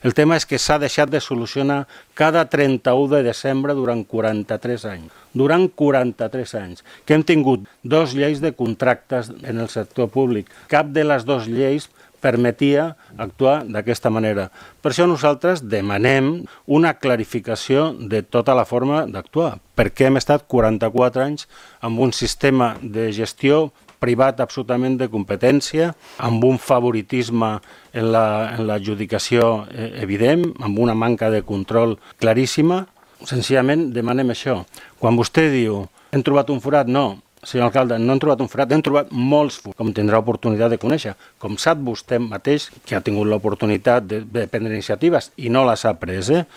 Debat tens en l’última sessió plenària per les posicions enfrontades entre govern i oposició sobre la gestió del bar El Paso, que posarà punt final a la seva activitat amb el canvi d’any.